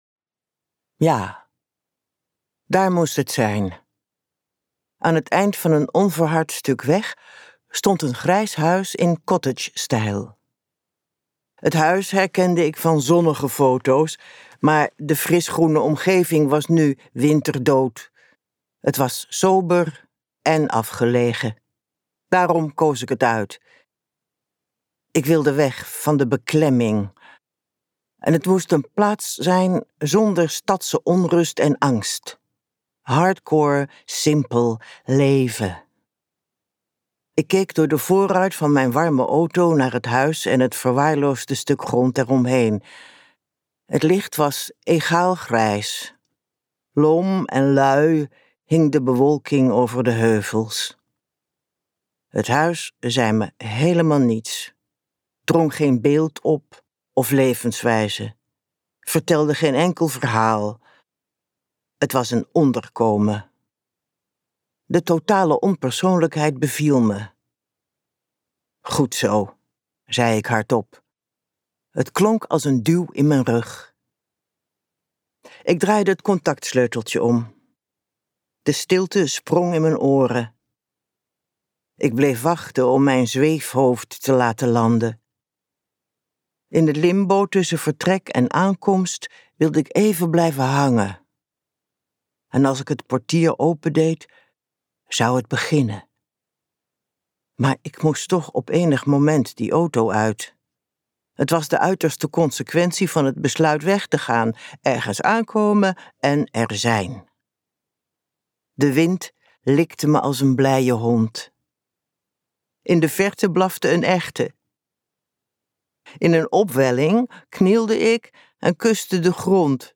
Het bewind van de gelukkigen Auteur: Nelleke Noordervliet Luister een fragment Direct bestellen In een Europa zonder vrije pers probeert een voormalig journalist op het platteland te ontsnappen aan het opgelegde geluk van het regime.
Zoals al haar boeken voortreffelijk ingesproken door Nelleke Noordervliet zelf.